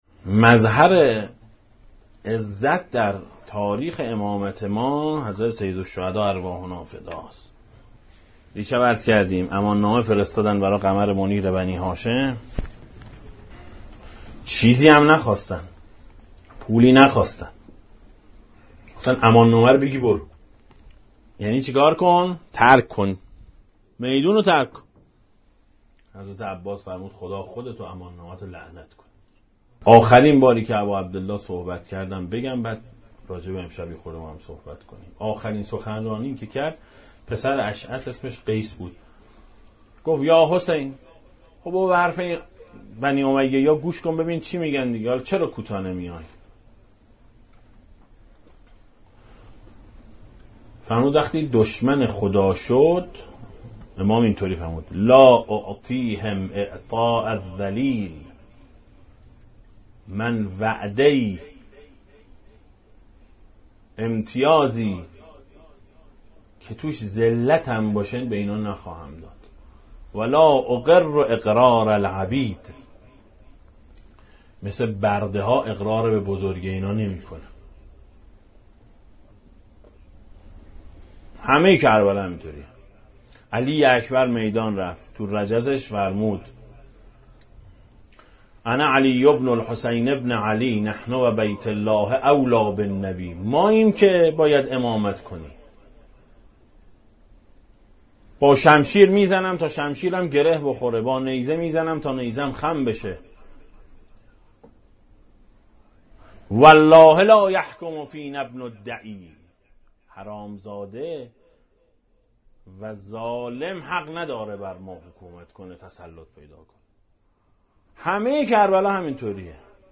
روضه شب عاشورای حسینی سال 1395 ـ مجلس اول
دسته: امام حسین علیه السلام, روضه های اهل بیت علیهم السلام, سخنرانی ها